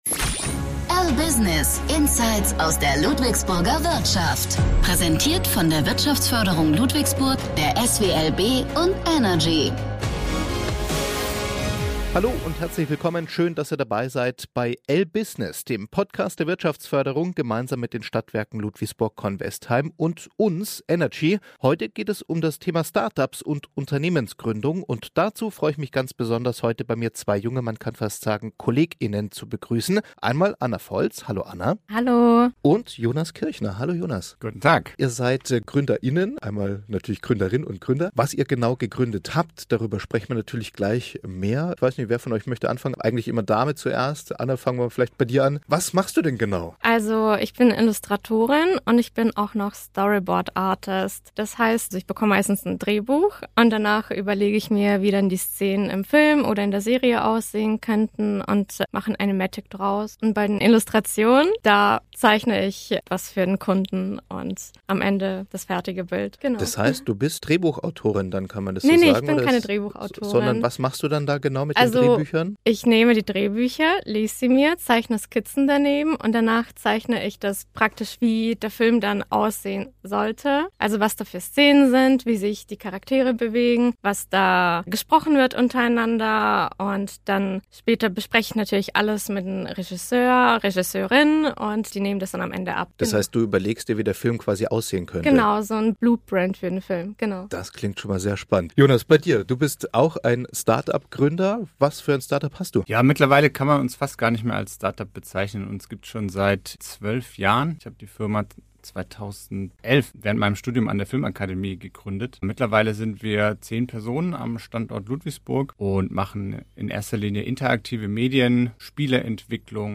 Beschreibung vor 2 Jahren Im neuen Podcast der Ludwigsburger Wirtschaftsförderung „LBusiness“ sprechen Menschen von etablierten Unternehmen, Start-ups und Stadtverwaltung über Wirtschaft und Zukunft in Ludwigsburg, moderiert durch das lokale Studio von Radio Energy.